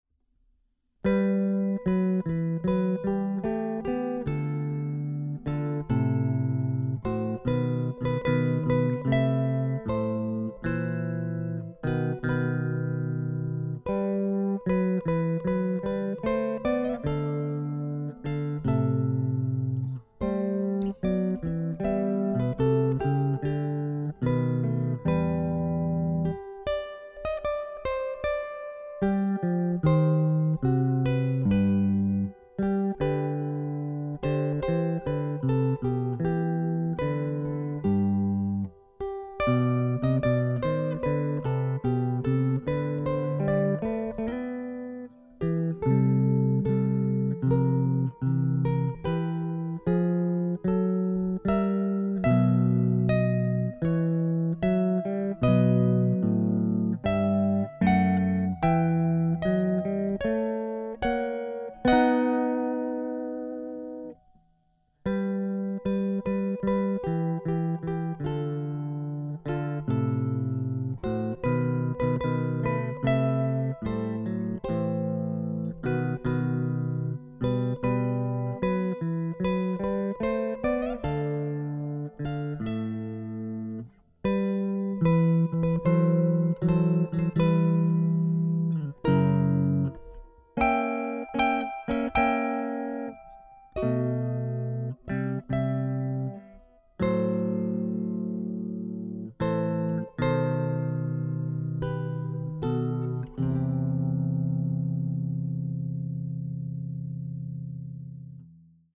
Ukulele Tunes